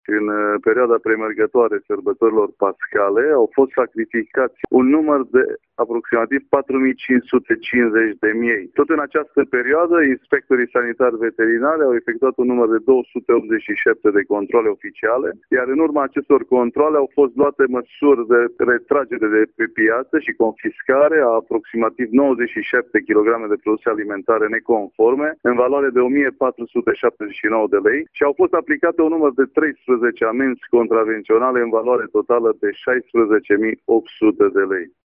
Șeful Direcției Sanitar Veterinare și pentru Siguranța Alimentelor Mureș, Vasile Oprea: